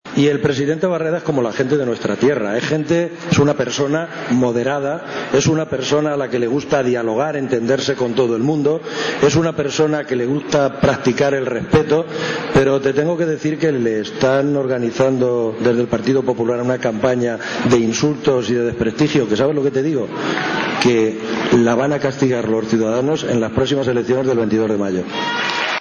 Alrededor de 6.000 personas han arropado hoy al presidente Barreda en el acto que los socialistas castellano-manchegos han celebrado en la localidad de Alcázar de san Juan (Ciudad Real).
El encuentro de los socialistas comenzó con las intervenciones de los secretarios generales del partido, que acompañados por las candidaturas autonómicas y de las principales localidades de la Región, respondieron a unas preguntas formuladas por una presentadora.